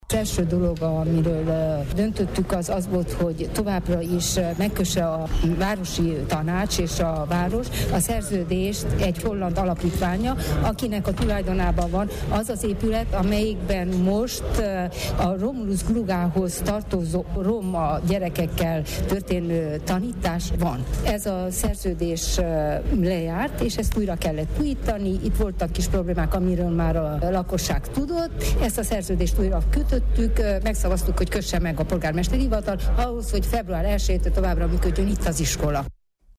Februártól ismét működhet a hidegvölgyi Szivárvány iskola, a Marosvásárhelyi önkormányzat ugyanis, mai rendkívüli ülésén jóvá hagyta annak a szerződésnek a meghosszabbítását, melyet a tanintézmény épületének tulajdonosával, egy holland alapítvánnyal kötöttek meg. Furó Judit RMDSZ-es tanácsost kérdeztük.